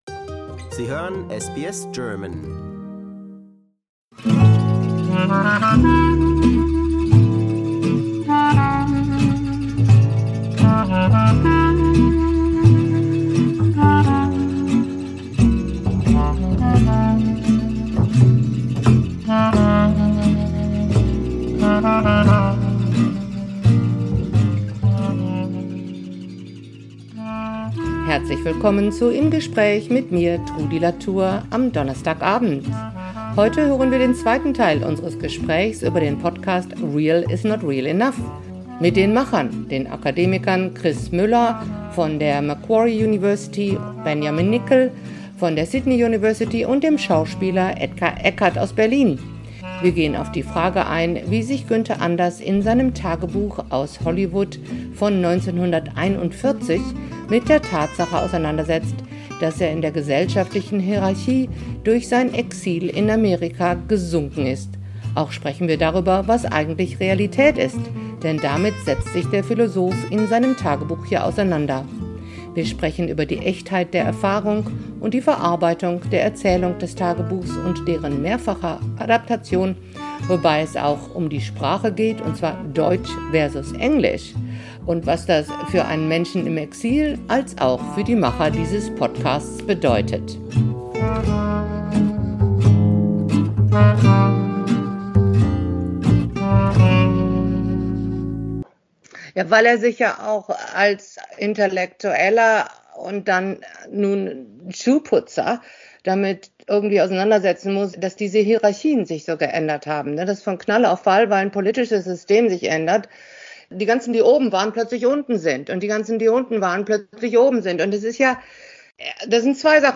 Viererdiskussion über "Real is not Real Enough" Source